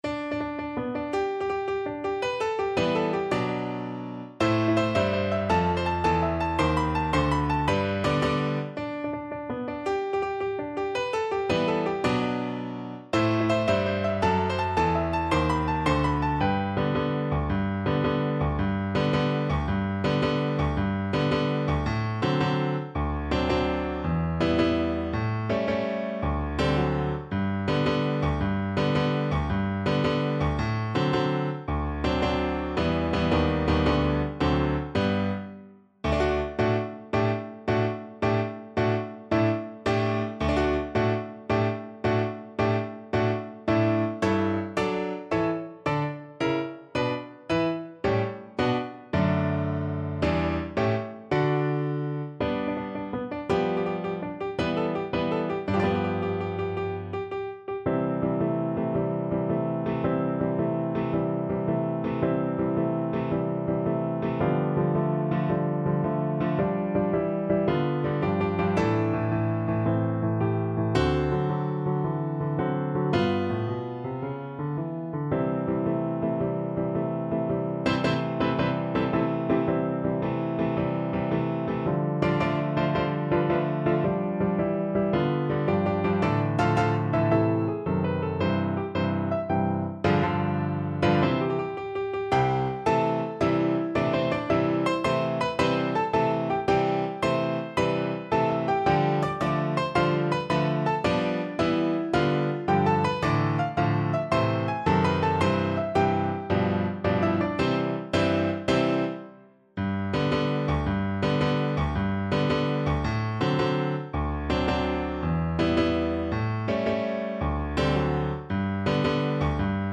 6/8 (View more 6/8 Music)
G major (Sounding Pitch) (View more G major Music for Viola )
March .=c.110
Classical (View more Classical Viola Music)